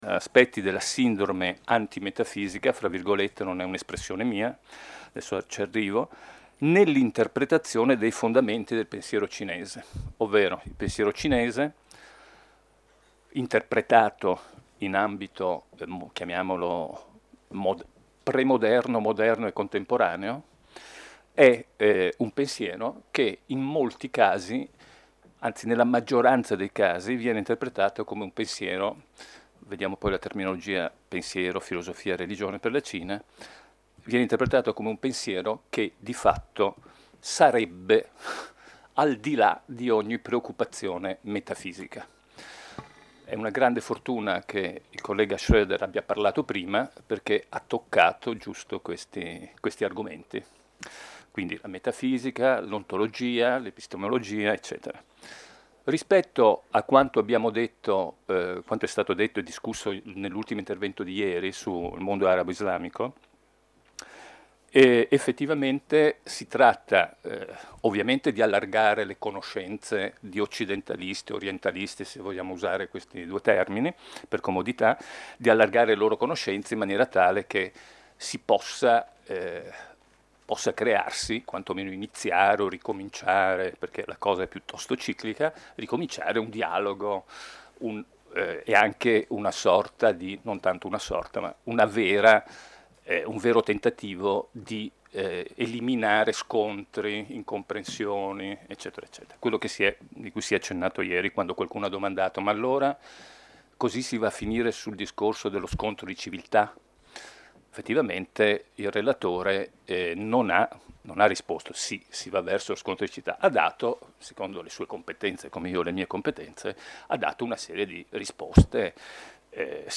FILOSOFIE NEL MONDO 64° Convegno del Centro Studi Filosofici di Gallarate Convegno Caricamento video in corso ...